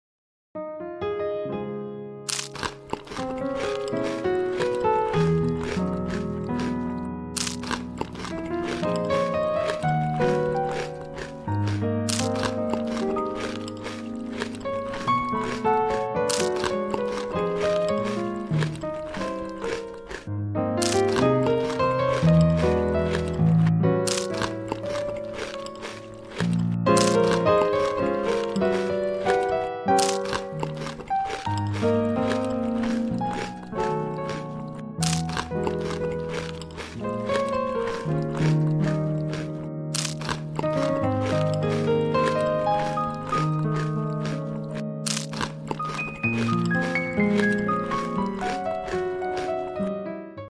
Random Yellow food MUKBANG sound effects free download